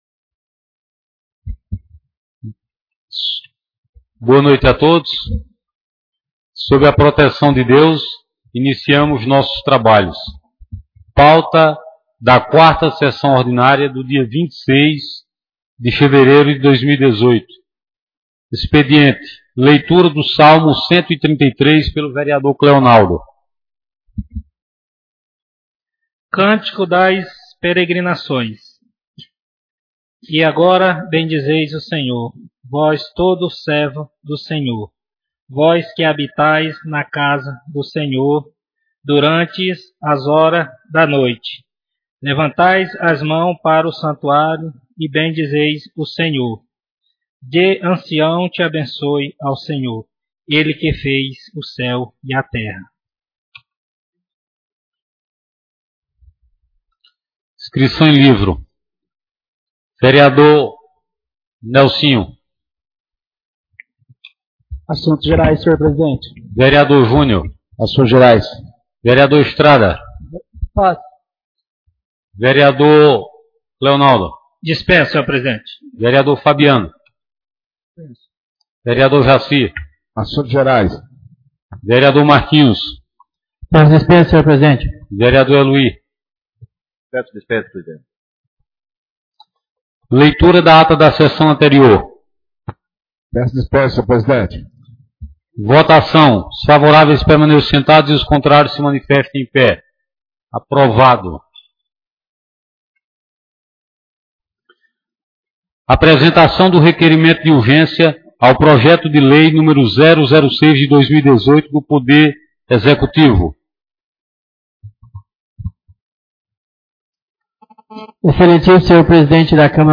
4ª Sessão Ordinária — Câmara Municipal de Ipiranga do Norte
4ª Sessão Ordinária